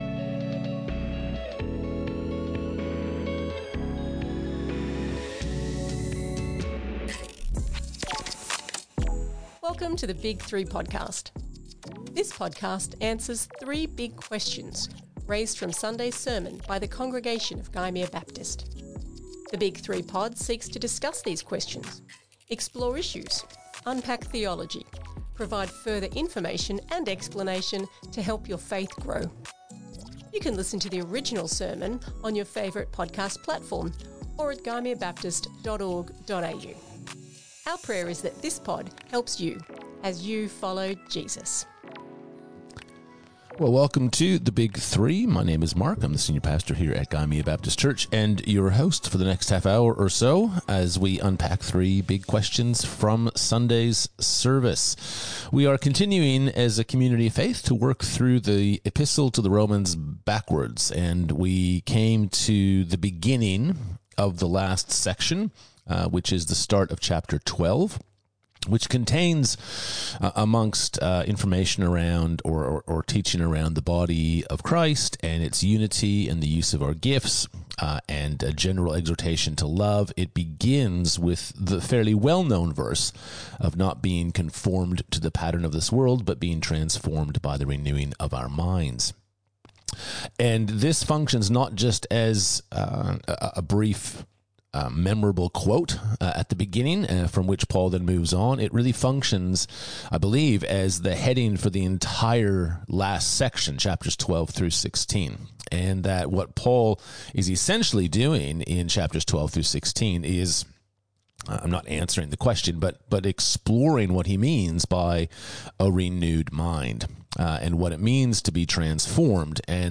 2026 Current Sermon The Big 3